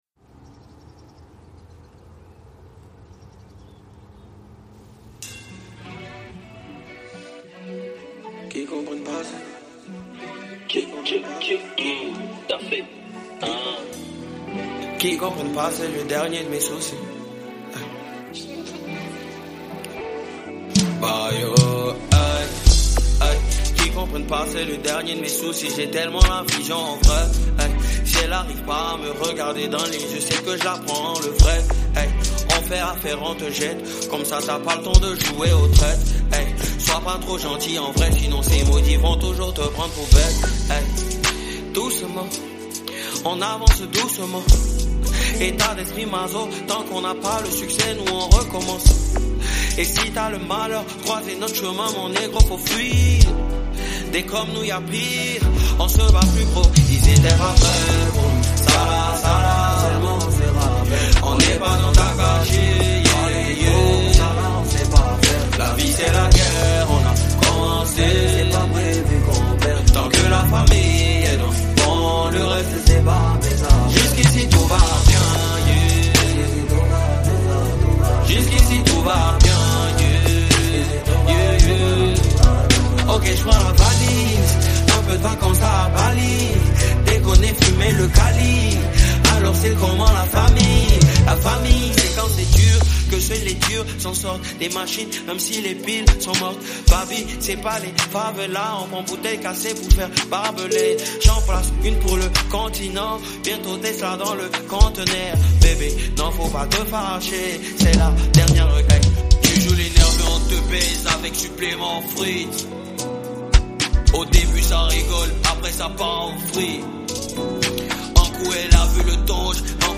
Rap ivoire